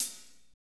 Index of /90_sSampleCDs/Northstar - Drumscapes Roland/DRM_Fast Rock/KIT_F_R Kit Wetx
HAT F R H1BL.wav